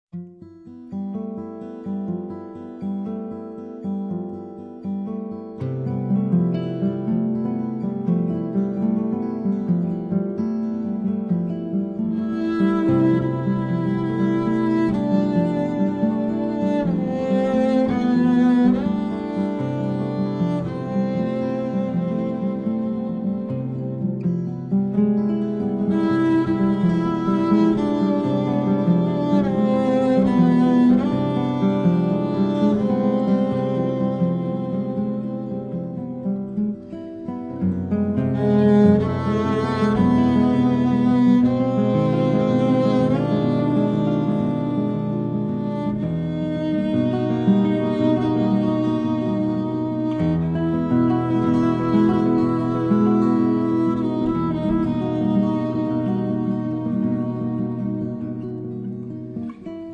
Chitarra Acustica e elettrica
sassofoni
contrabbasso